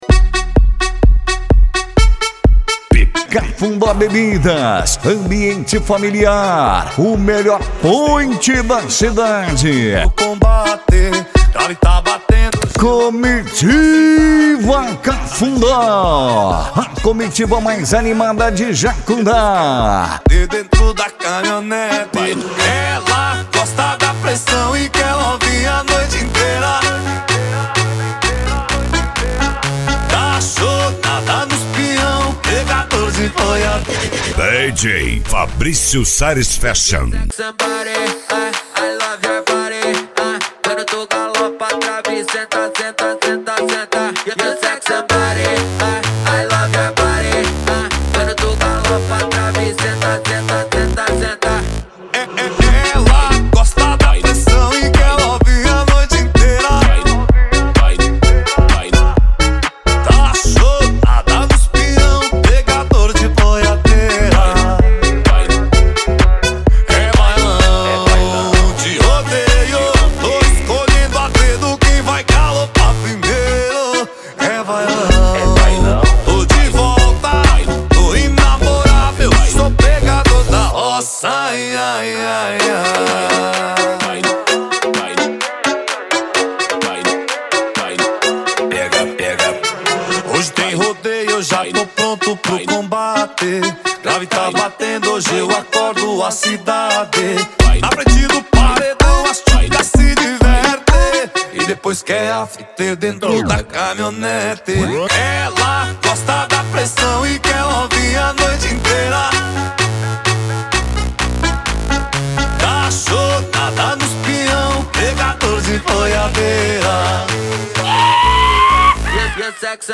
Country Music
Funk
Funk Nejo
SERTANEJO